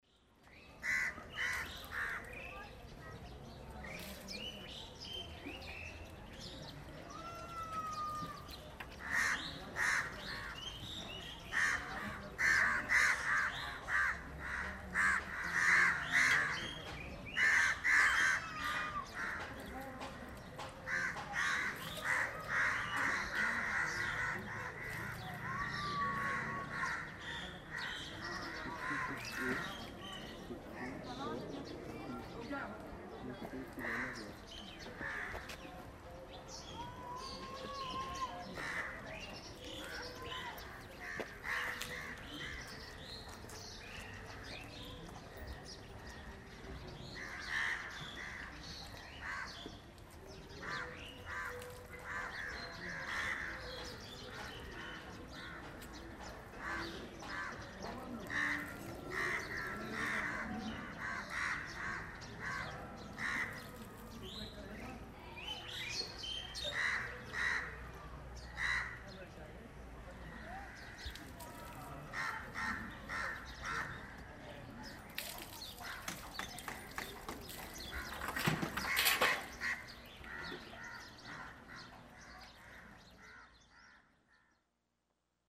Soundcities, residential area vendor’s place1:25
hildegard_westerkamp_-_13_-_residential_area_vendor_s_place.mp3